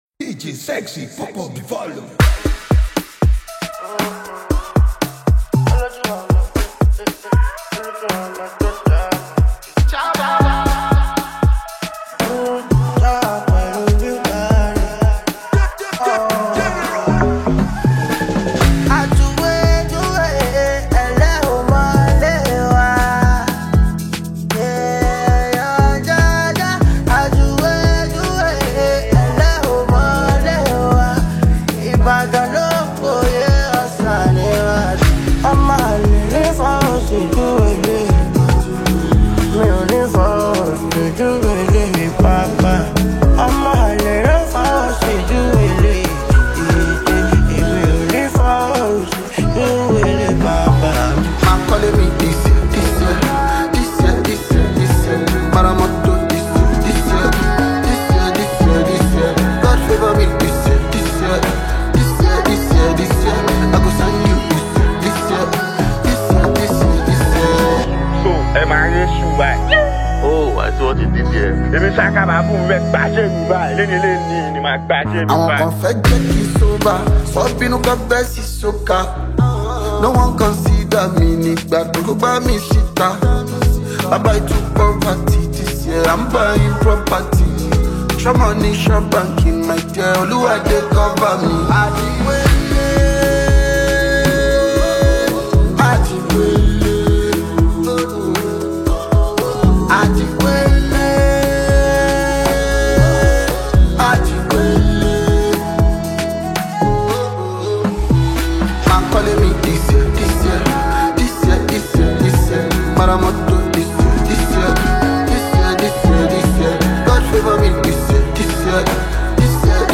fuji-infused vocals, adding a raw and emotional texture